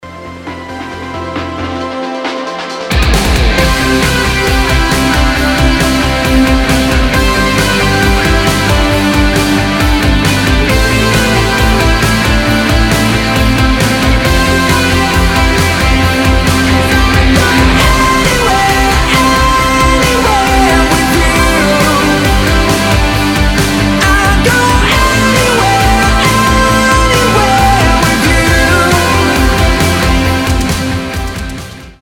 Electronic
вдохновляющие
Retrowave
воодушевляющие
Стиль: synth rock, synthwave